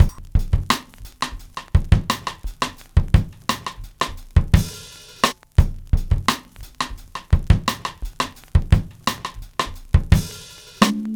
Index of /90_sSampleCDs/Best Service ProSamples vol.40 - Breakbeat 2 [AKAI] 1CD/Partition B/MEANGREEN086